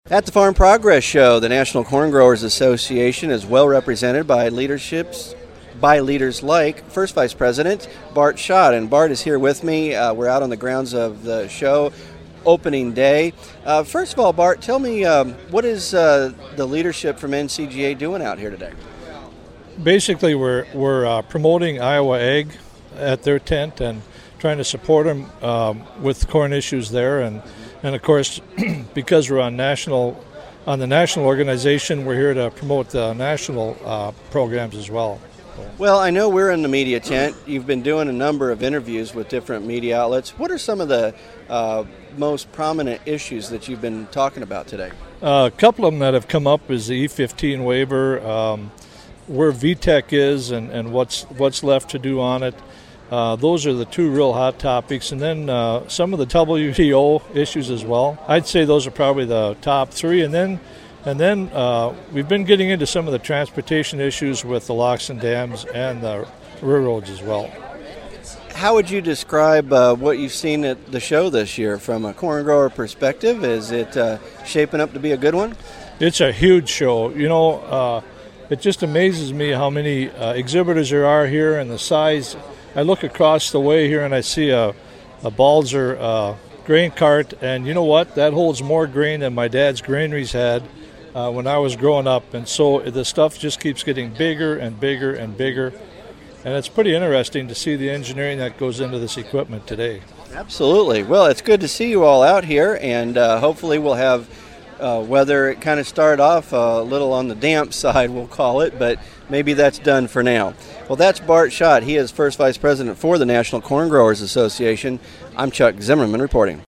Corn Talking At Farm Progress Show